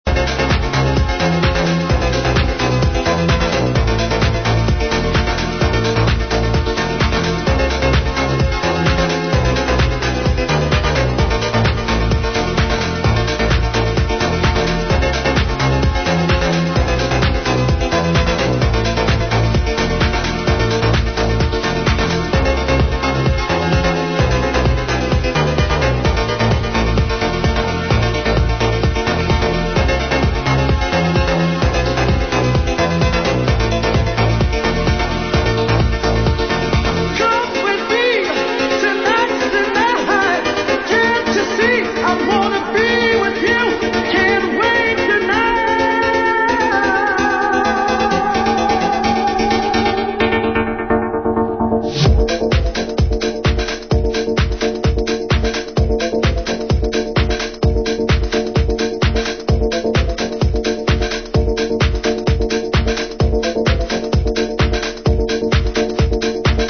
s23- deep house with oldschool vocal